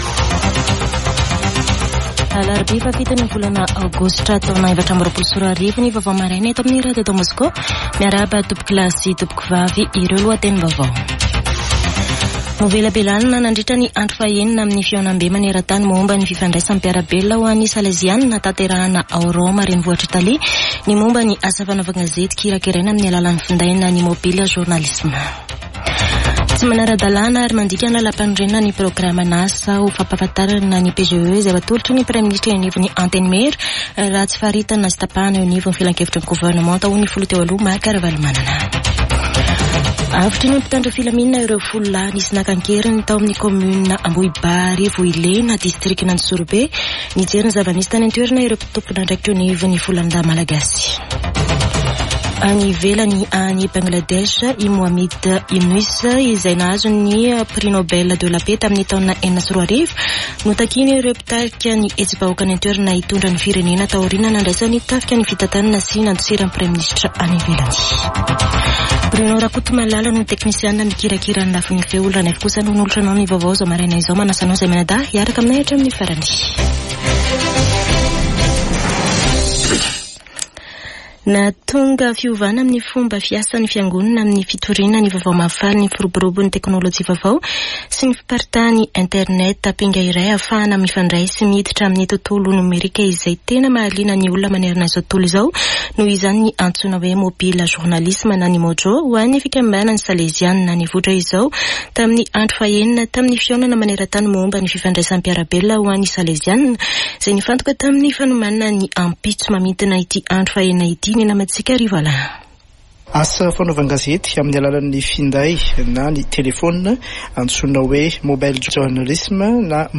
[Vaovao maraina] Alarobia 7 aogositra 2024